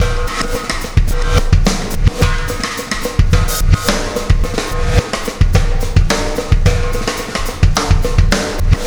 Black Hole Beat 11.wav